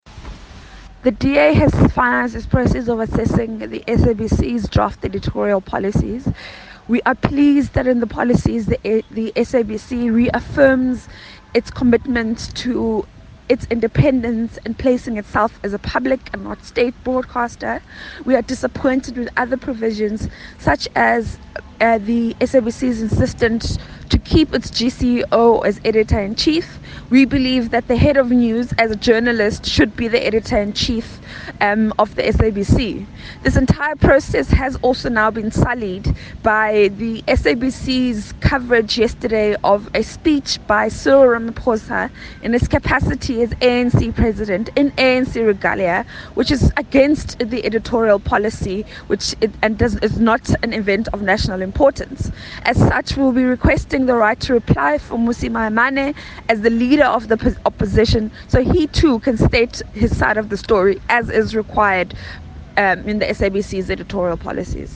soundbite by the DA Shadow Minister of Communications, Phumzile Van Damme MP.